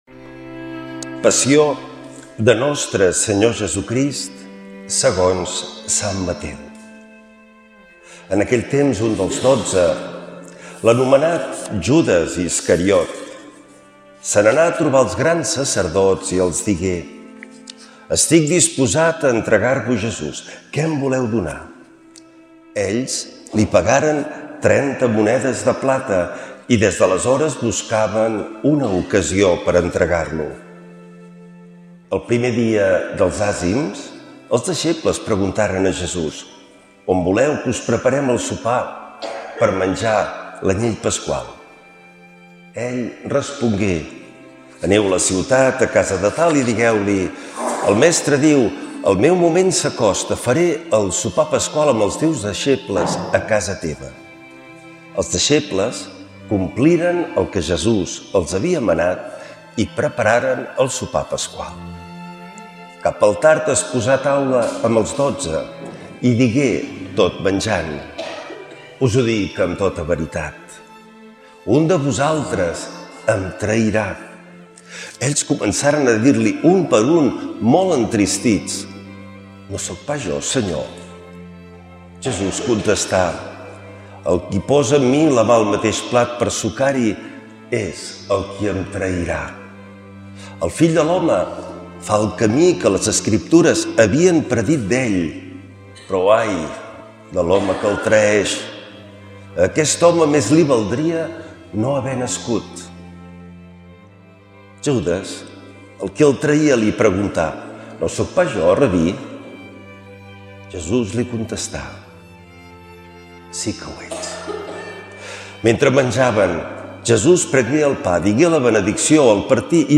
Lectura de l’evangeli segons sant Mateu.